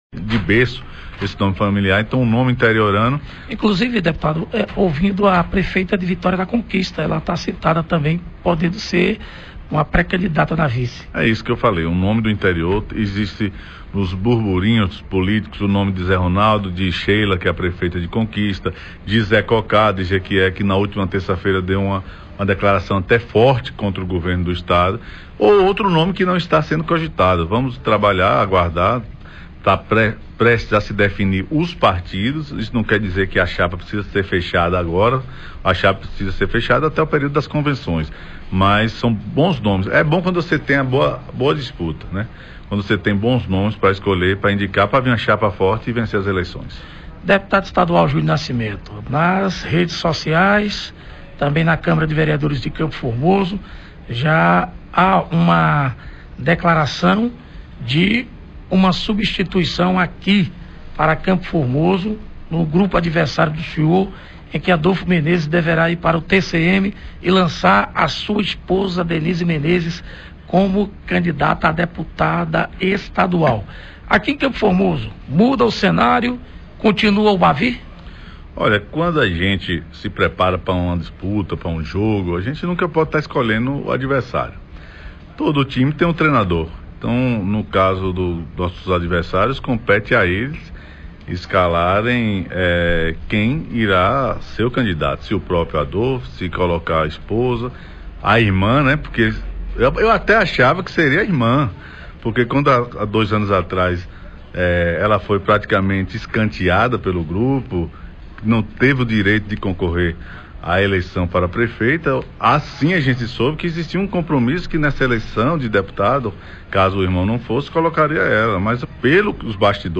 Deputado estadual Júnior Nascimento fala sobre emendas parlamentares para o município de CFormoso entre outros assuntos.